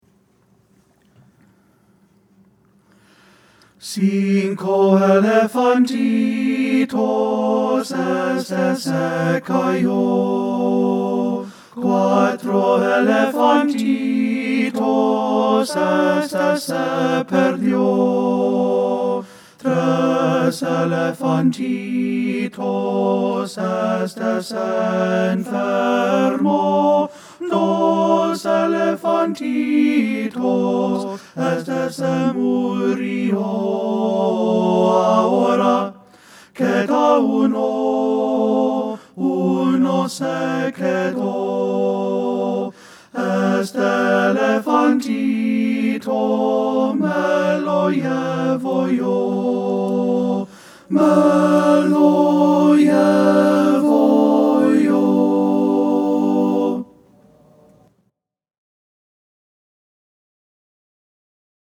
Ensemble: Tenor-Bass Chorus
Key: G major
Tempo: q = 120, q = 110, q. = 88